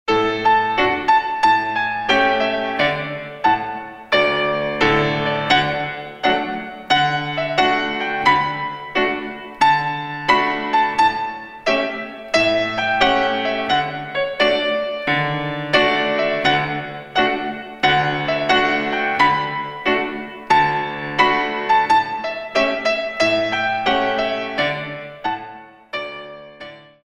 64 Counts